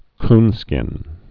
(knskĭn)